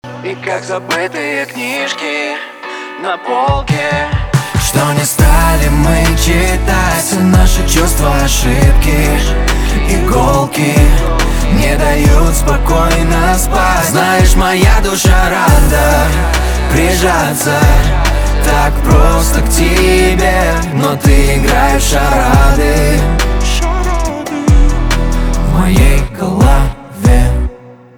грустную мелодию про любовь на звонок.
поп грустные
гитара
чувственные